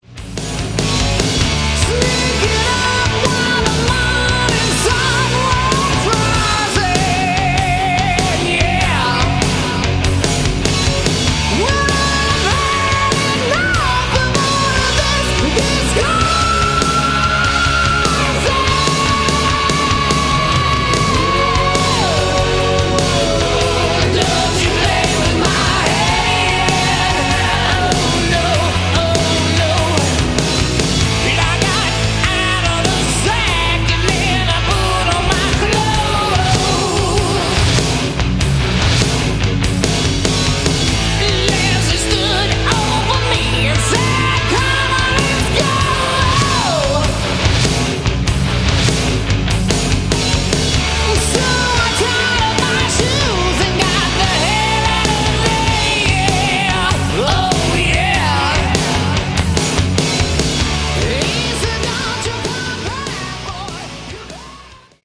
Category: Hard Rock
lead vocals
keyboards, vocals
bass, vocals
drums